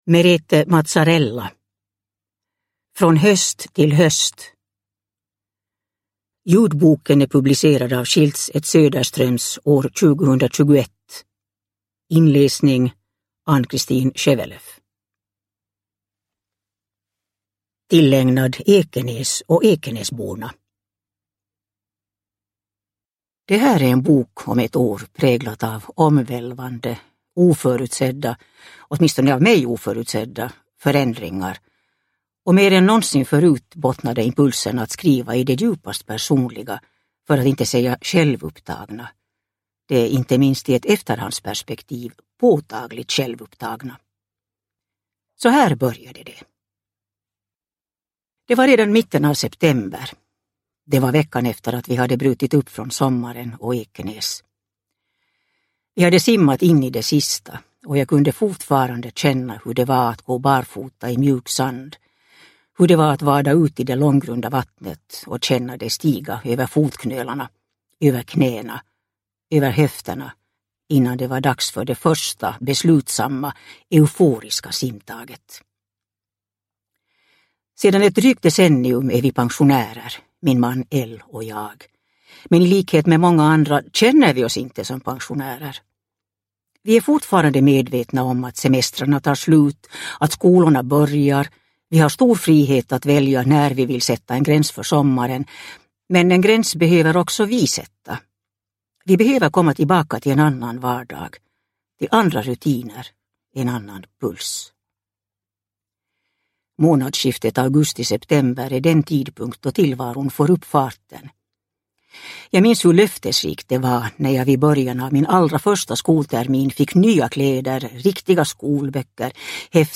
Från höst till höst – Ljudbok – Laddas ner